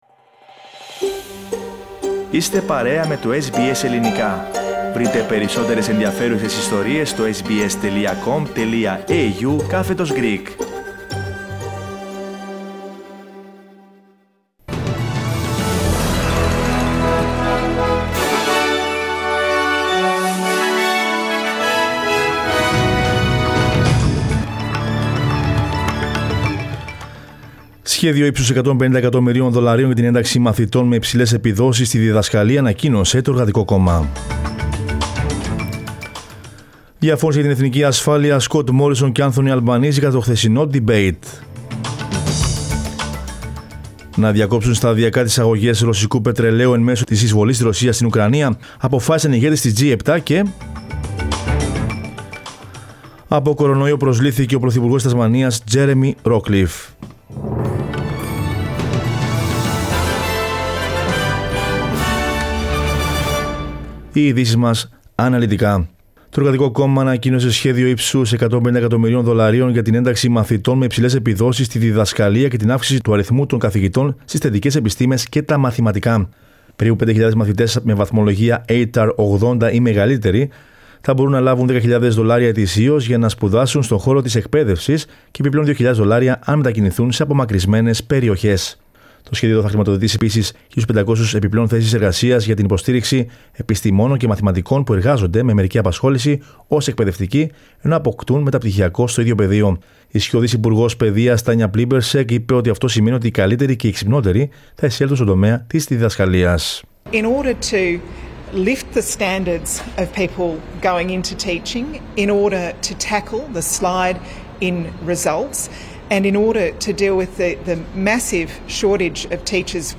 Δελτίο Ειδήσεων Δευτέρα 09.05.22
News in Greek. Source: SBS Radio